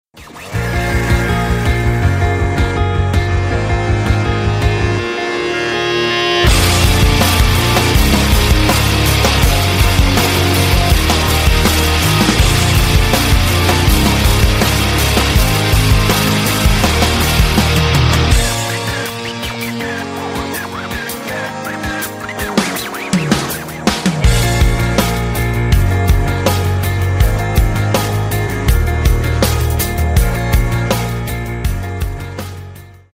Альтернатива
Рок Металл